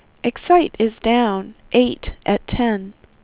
WindowsXP / enduser / speech / tts / prompts / voices / sw / pcm8k / stock_23.wav